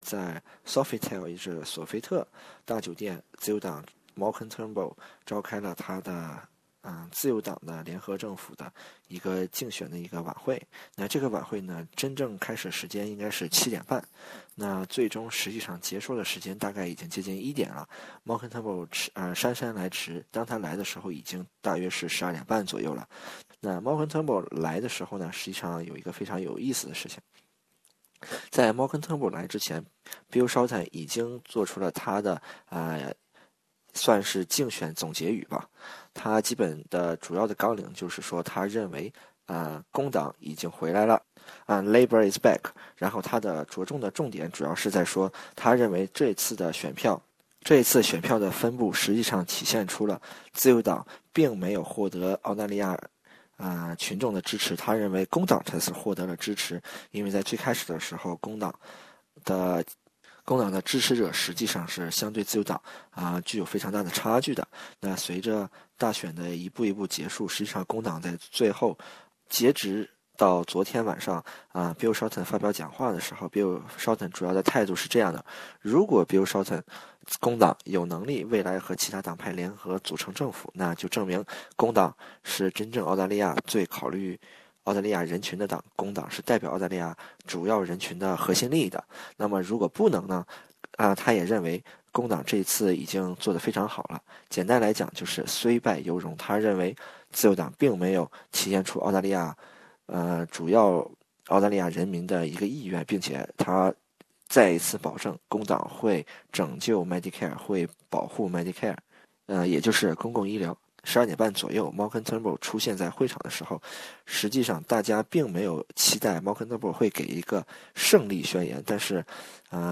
SBS 普通話電台